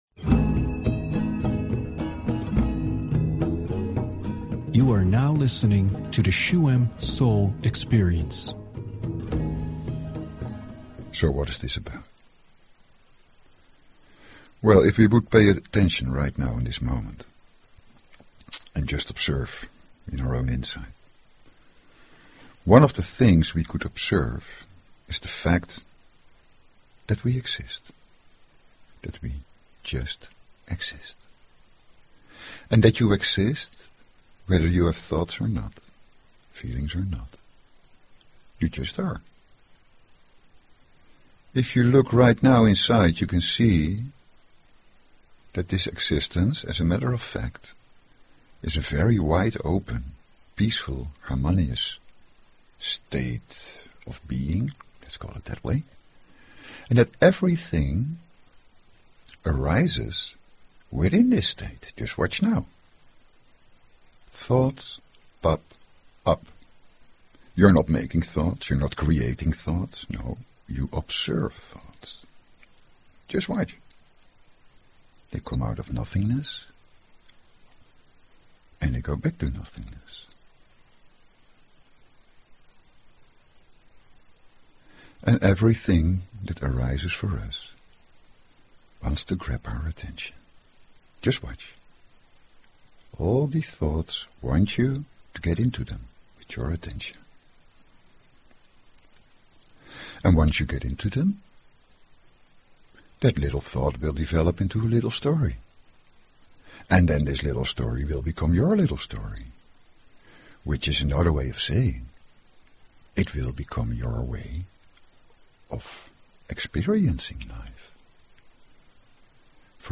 Talk Show Episode, Audio Podcast
This is not about positive thinking, but about building up inner strength and focus in order to create. The second part of the show is a Reset Meditation that helps to free your attention.